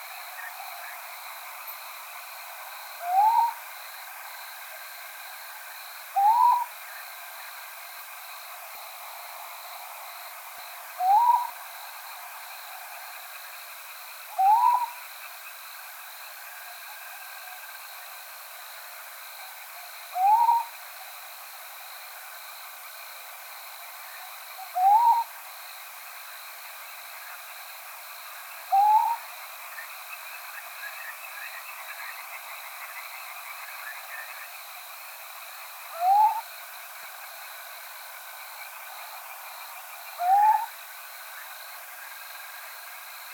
ATHENE NOCTUA - LITTLE OWL - CIVETTA
DATE/TIME: 14/april/2015 (9 p.m.) - IDENTIFICATION AND BEHAVIOUR: One bird - not in sight - is perched somewhere in a field near a farm house. Zone with a small river, cultivated fields, olive groves and hedgerows with trees. - POSITION: Località Bagnacci near Fonteblanda, Grosseto, LAT.N 42°33'/LONG.E 11°11' - ALTITUDE: +20 m. - VOCALIZATION TYPE: male hoot. - SEX/AGE: adult male. - COMMENT: This hoot type is much more whistled and has a relatively pure tone (no harmonics on the spectrogram); compare it with the previous recording. Background: very strong amphibian calls. Note that there is also a hint of echo in this recording (see spectrograms). - MIC: (WA)